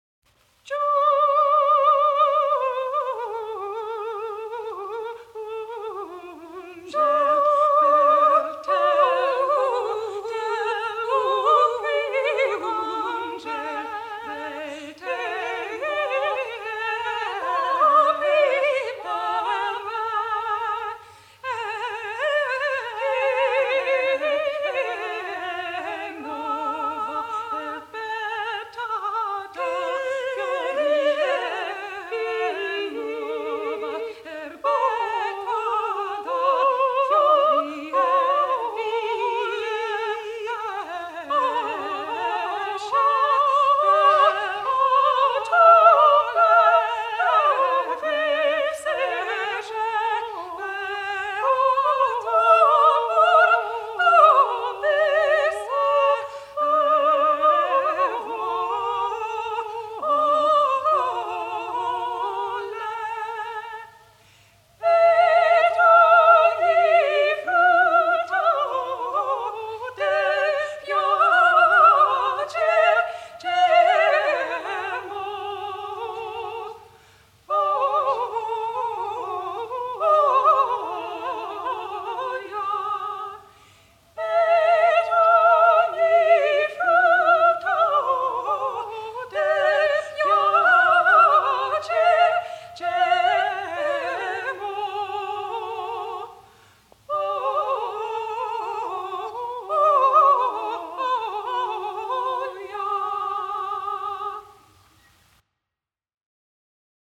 The ‘caccia-madrigal’ is a canon where a second voice sings the same music as the first voice but starting a bar or two later and throughout the piece tries to “caccia-up’.
sopranos